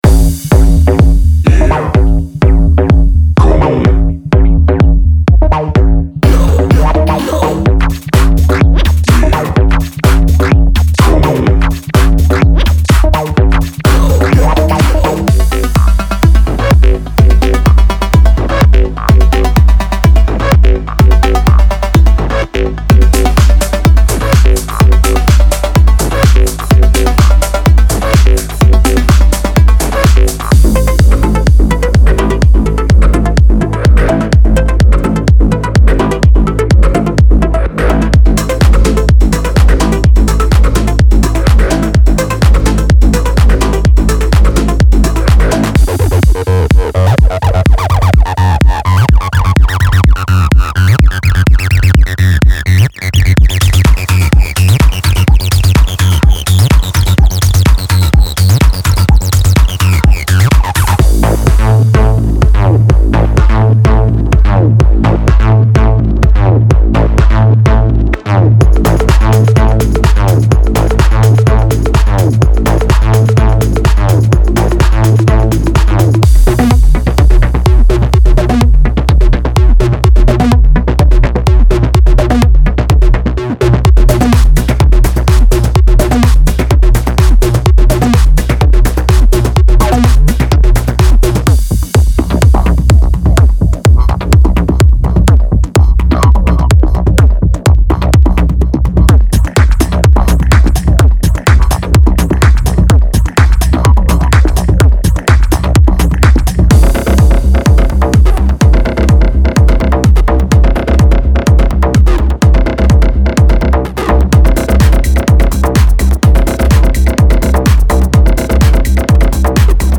• Punchy drums that drive your tracks forward
• In-your-face synth loops that captivate and energize
• Top-notch percussion loops that add rhythm and flair
• Tempo: 126 BPM